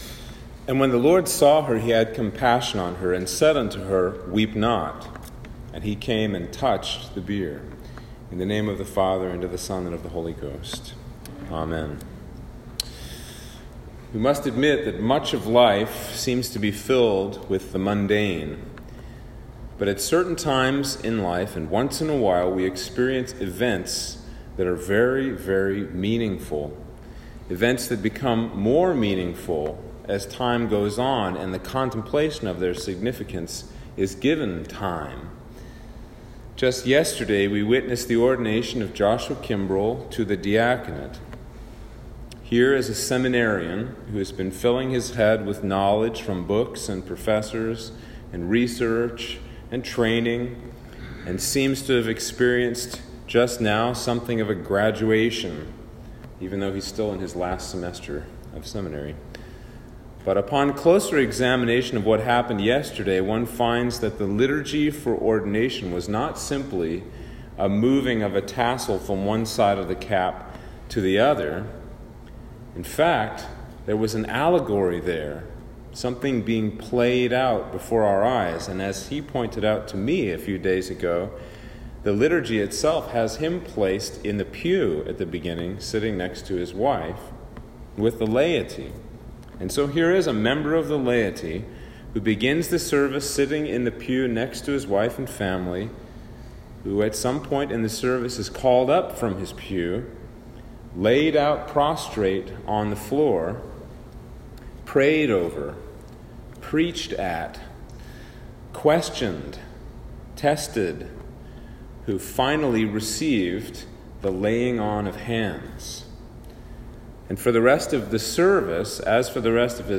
Sermon for Trinity 16 - 2021